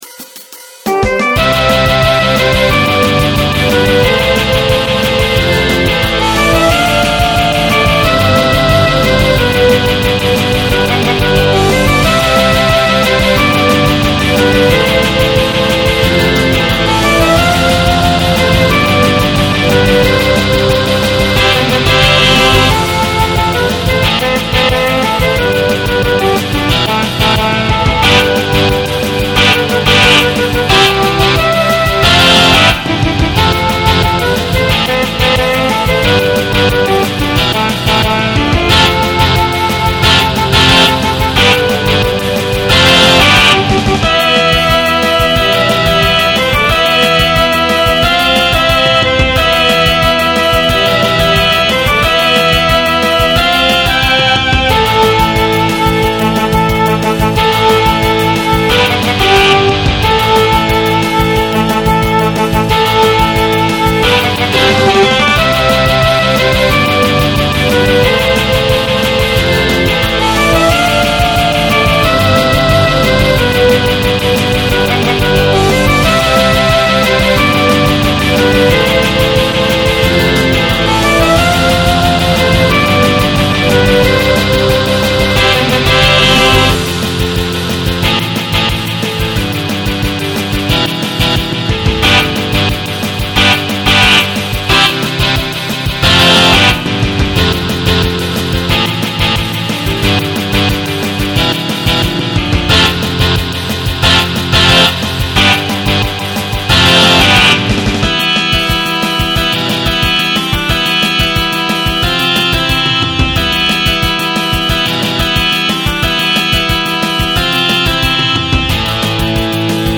モノラル   エレキギターの音に迫力があったため選択しました。